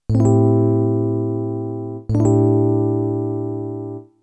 Am7 ... becomes ... A bass + C mu major triad = A D E G (= A7sus4)
A7sus4 (Cmu/A) =   x 0 12 9 8 x
Listen Sound Example: listen to the two chords
am7_mu.wav